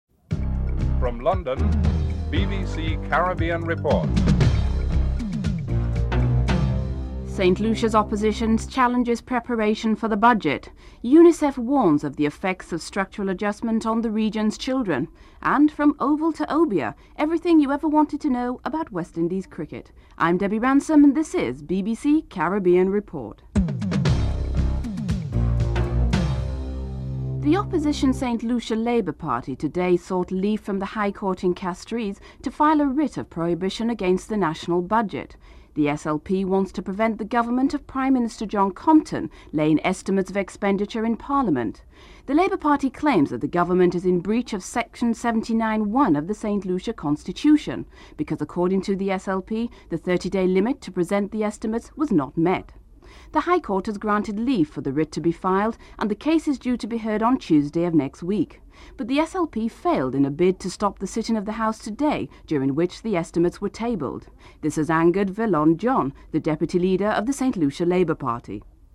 8. Recap of top stories (14:44-15:05)